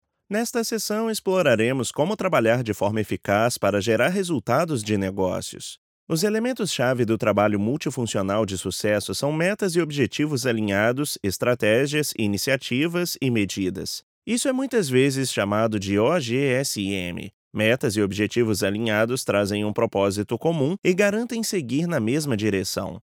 अत्याधुनिक तकनिकी के साथ प्रोफेशनल स्टूडियो
न्यूमैन टीएलएम 103 – जर्मन लाइन
कॉरपोरेट प्रेजेंटेशंस